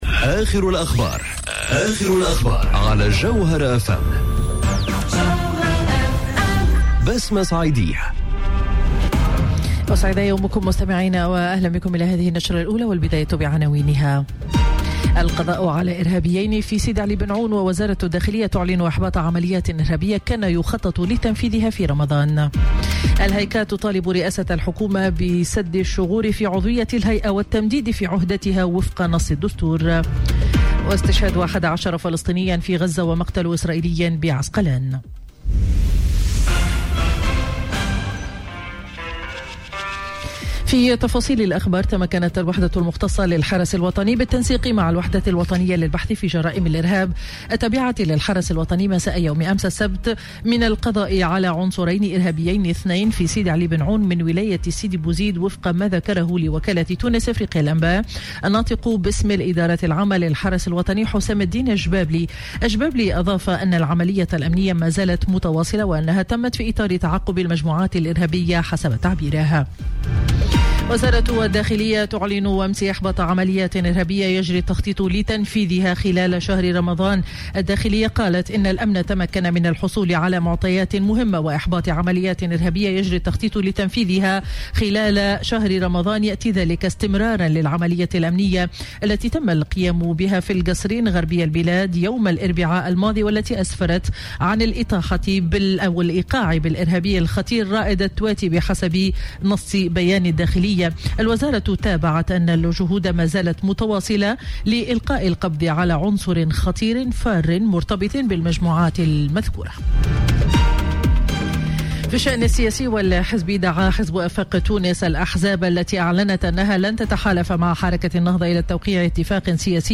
نشرة أخبار السابعة صباحا ليوم الأحد 05 ماي 2019